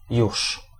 Ääntäminen
RP : IPA : /jɛt/ US : IPA : [jɛt]